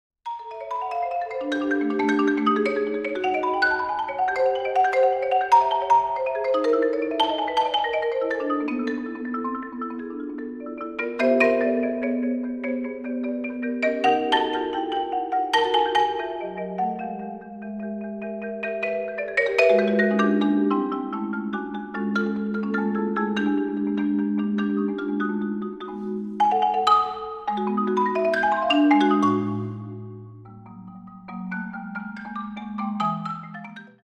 for Marimba